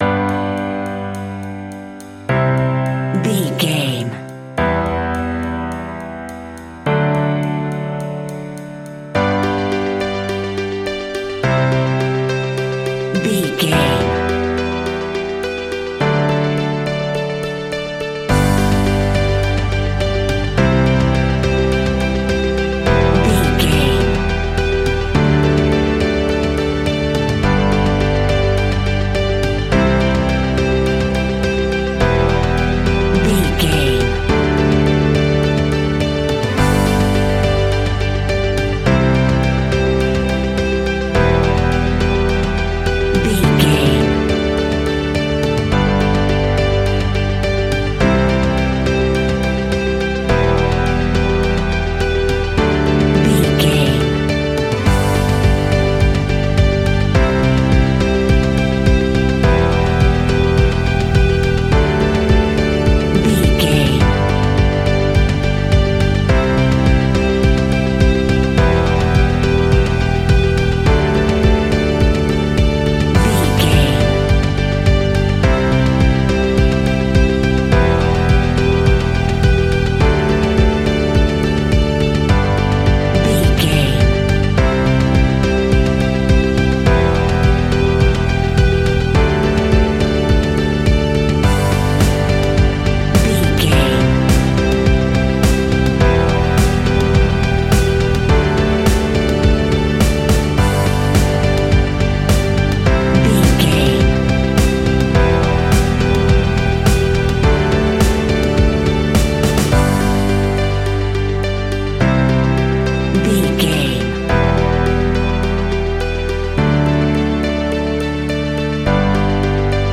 Uplifting
Ionian/Major
pop rock
energetic
motivational
cheesy
instrumentals
indie pop rock music
guitars
bass
drums
piano
organ